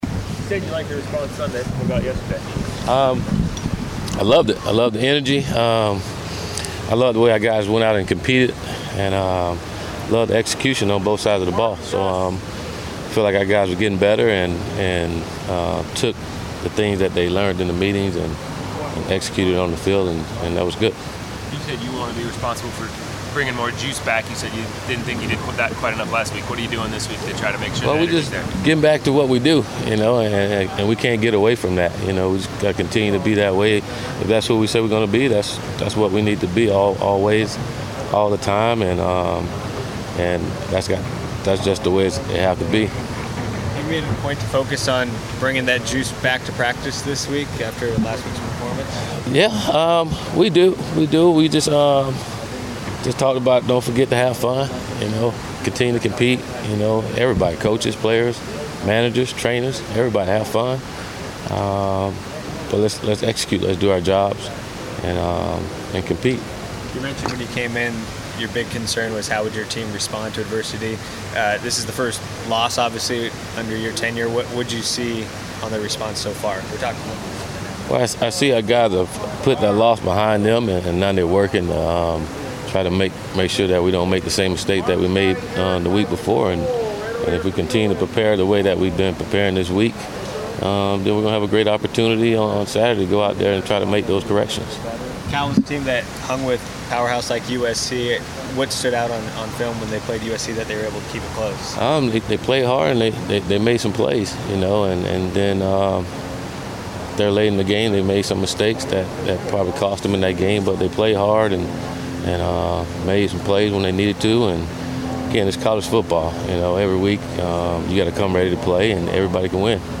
Willie Taggart Media Session 9-27-17